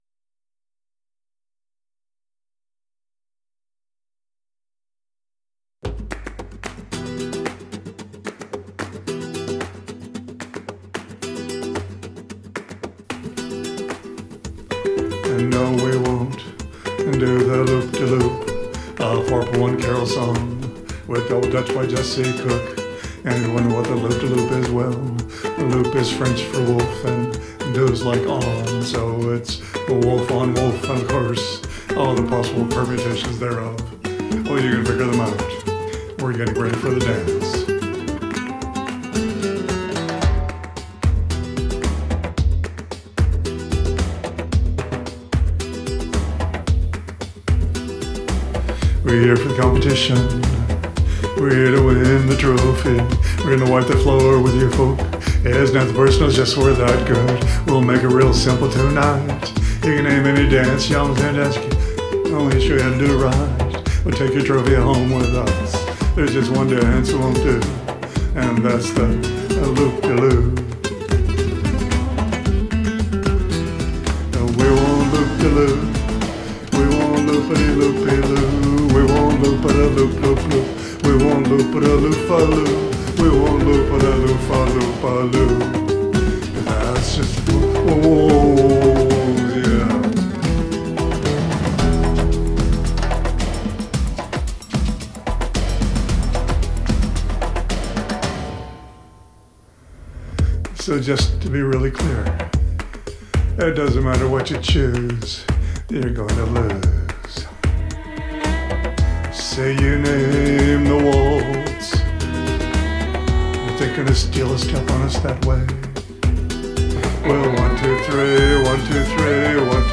It was energetic and boppy and I just liked it.